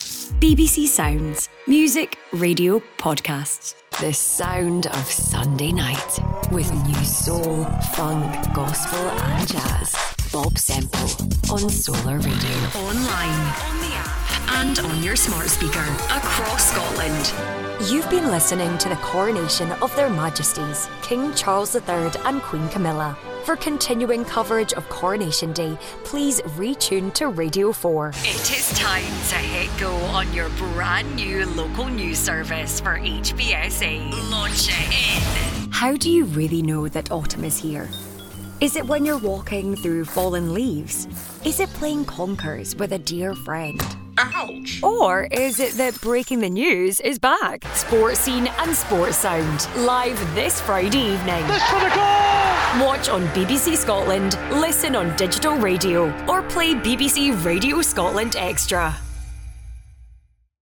Inglés (escocés)
Imágenes de radio
Estudio totalmente insonorizado con tratamiento acústico.
Contralto